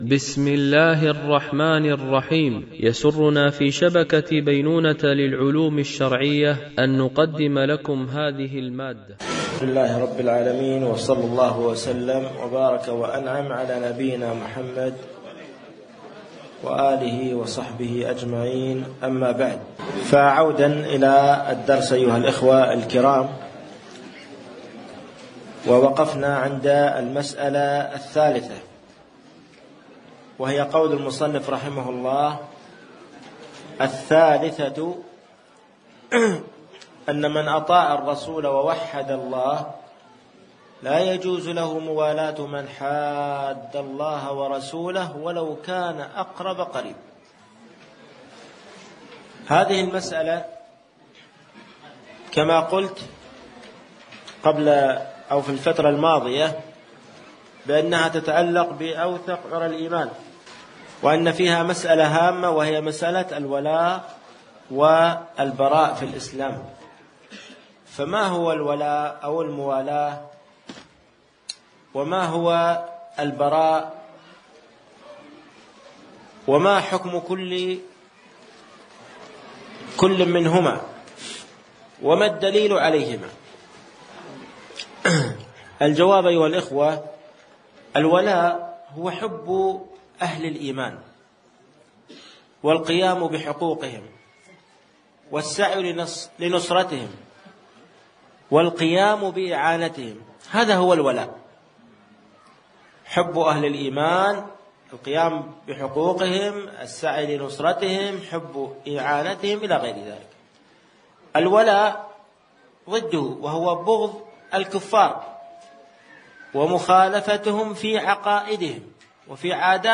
شرح الأصول الثلاثة ـ الدرس 05